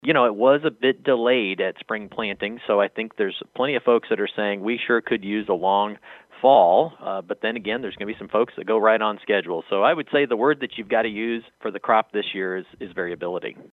NAIG SAYS THERE’S SOME HOPE THAT THE GROWING SEASON WILL EXTEND LATER INTO THE FALL.